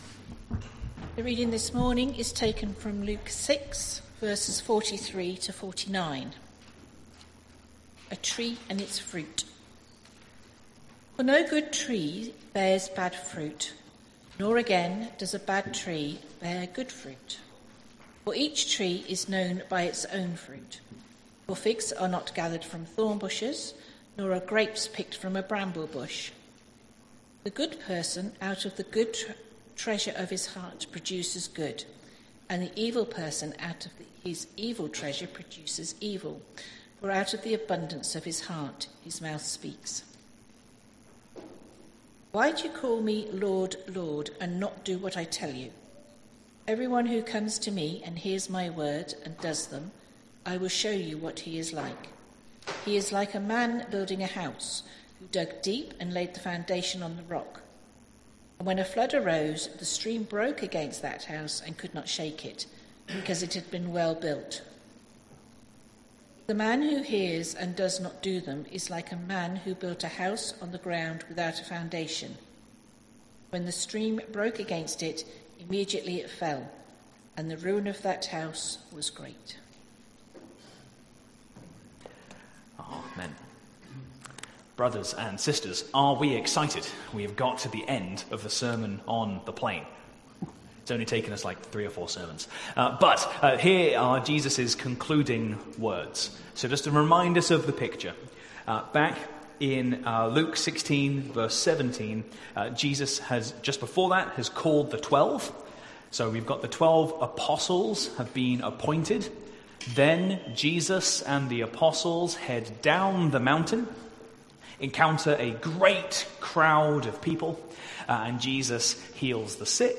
Sermon Series: Luke’s Gospel